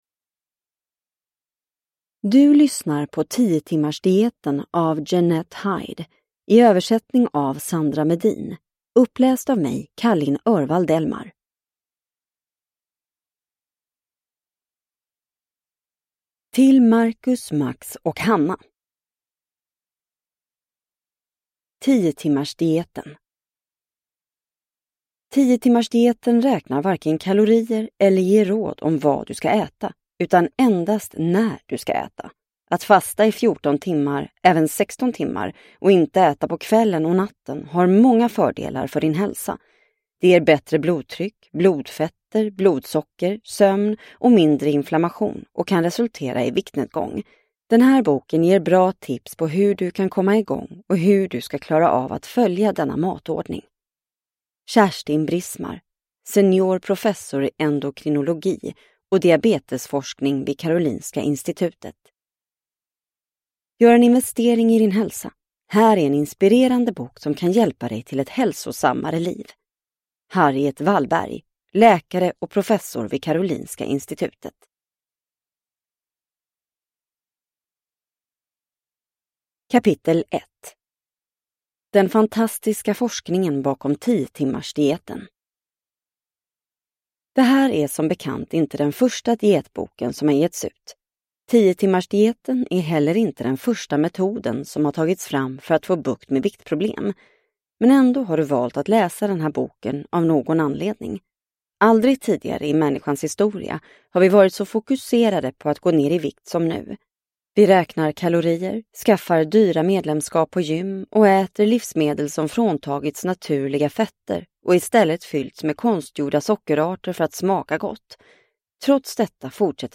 10-timmarsdieten : periodisk fasta för lägre vikt och starkare immunförsvar – Ljudbok – Laddas ner